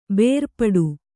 ♪ bērpaḍu